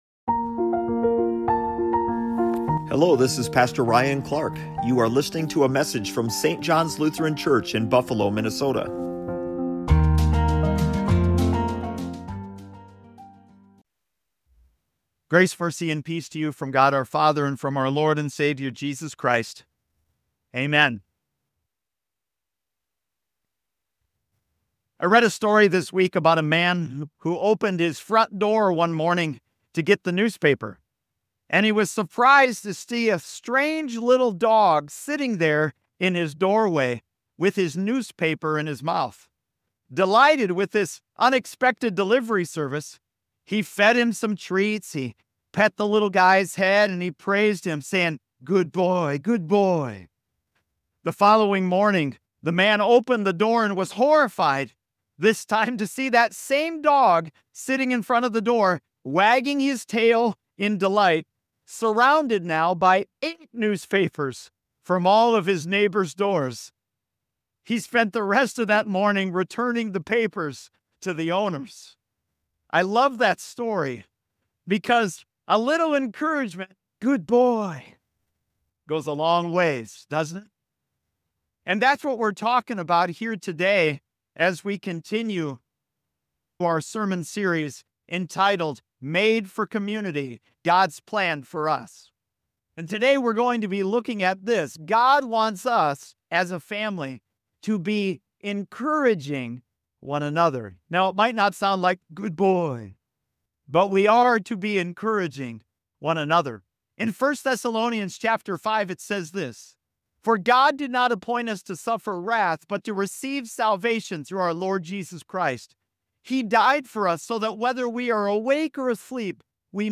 SERMON HOME ‘Strong people don’t put each other down. They lift each other up.’